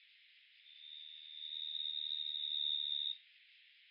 Existen muchas especies distribuidas en Norteamérica, que se diferencian por las características de los sonidos que producen.
Oecanthus_sp.mp3